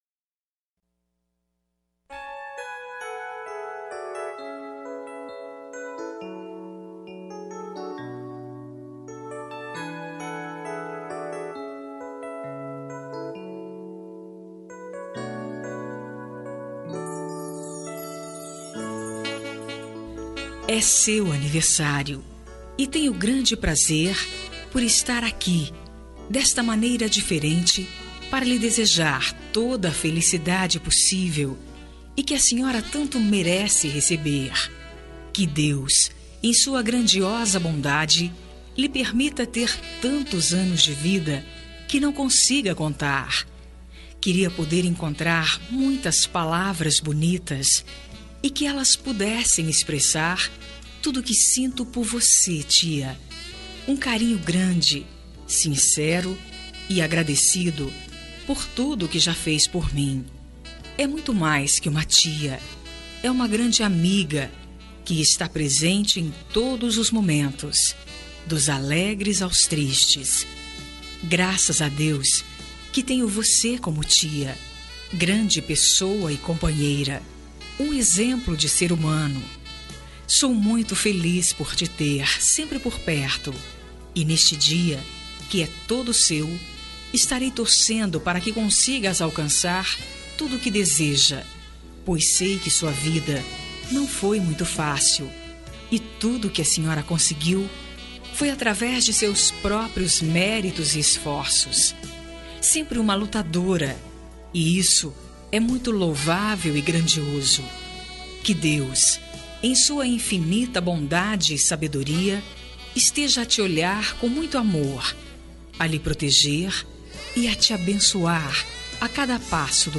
Aniversário de Tia – Voz Feminina – Cód: 9580